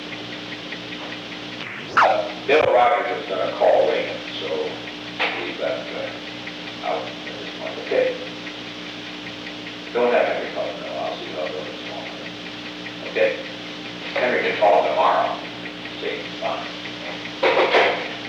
The Old Executive Office Building taping system captured this recording, which is known as Conversation 303-003 of the White House Tapes.
The President talked with an unknown person.